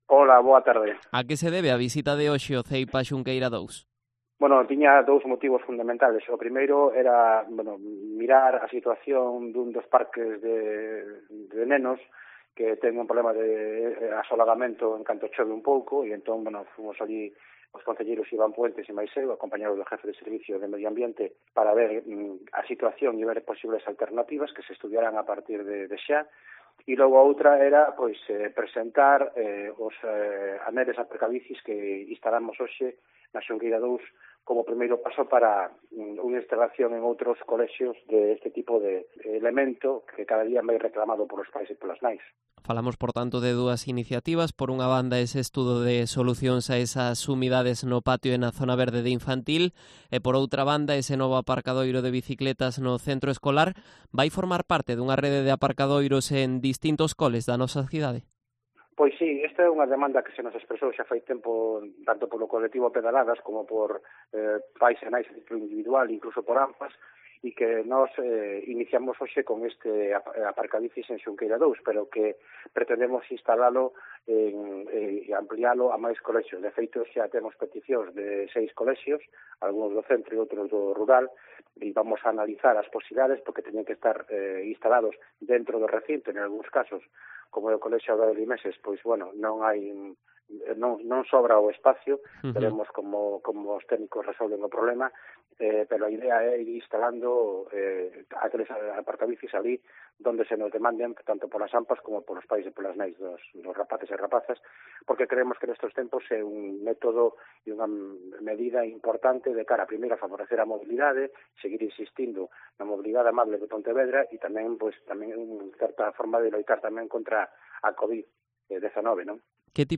Entrevista a Tino Fernández, concejal de Educación de Pontevedra